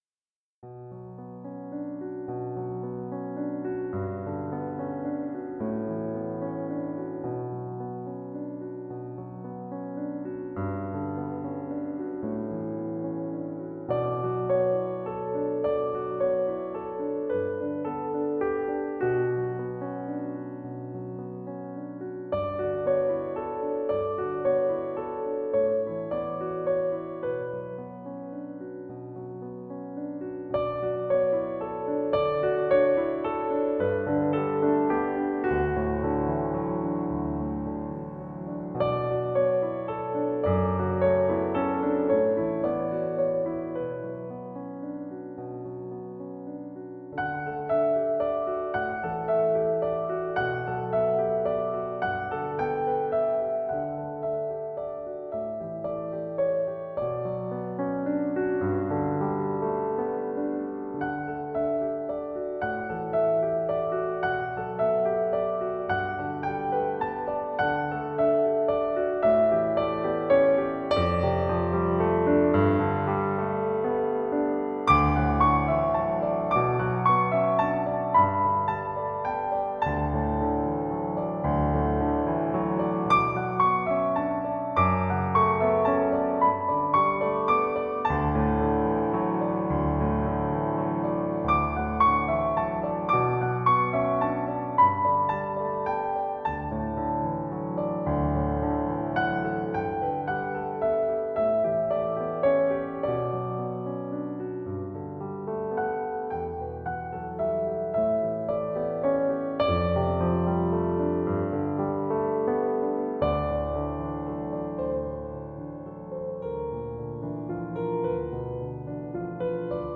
موسیقی بیکلام پیانو